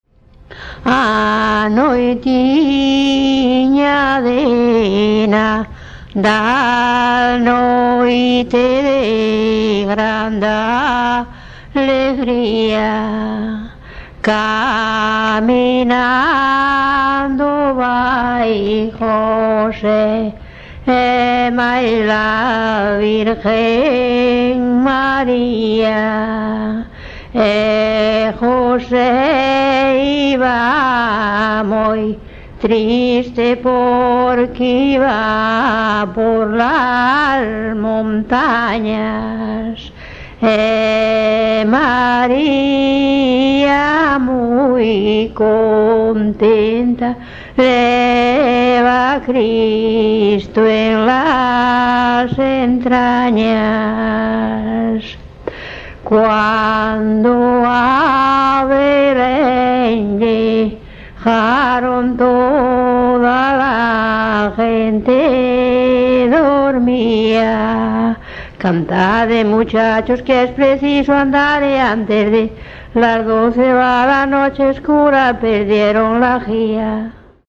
Tipo de rexistro: Musical
LITERATURA E DITOS POPULARES > Cantos narrativos
Soporte orixinal: Casete
Instrumentación: Voz
Instrumentos: Voz feminina
Tempo: 4=ca.100
Modo de interpretación: Rubato